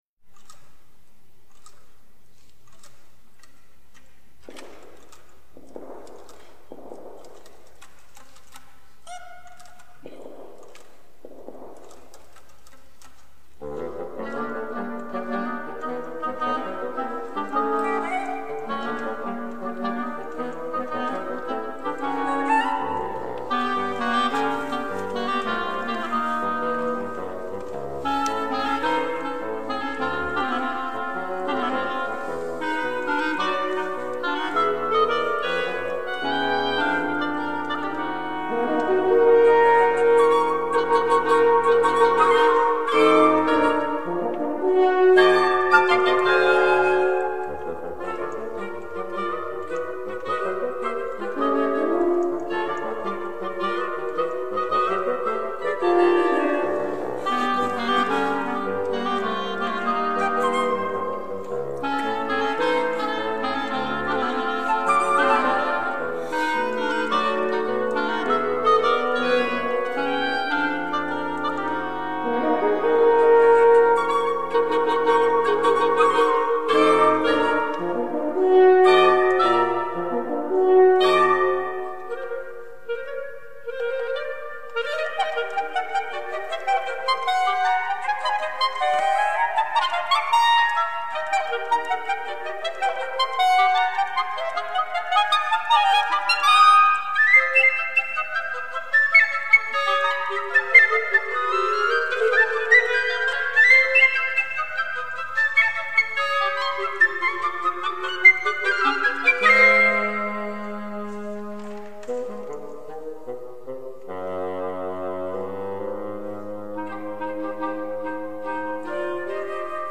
for Woodwind Quintet